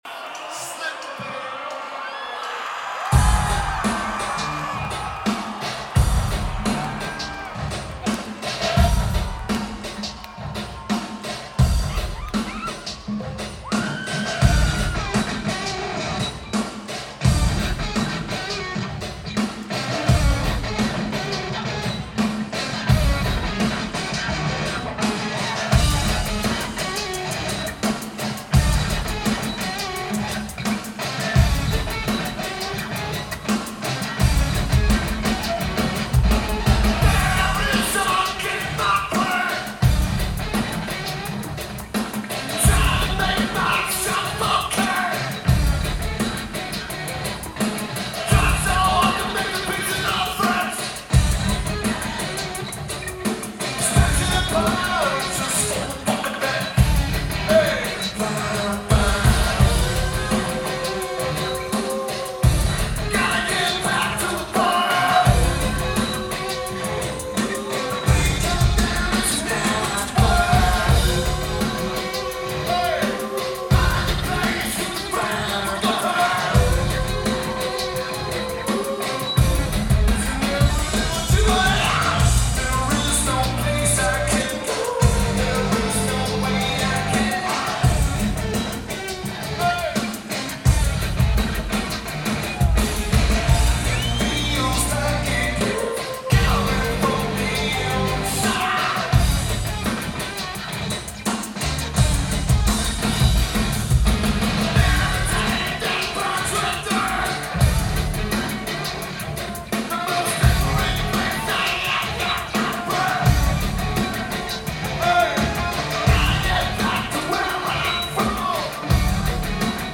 DTE Energy Music Theatre
Lineage: Audio - AUD (DPA 4060 (HEB) + BB + NJB3)